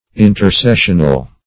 Intercessional \In`ter*ces"sion*al\, a.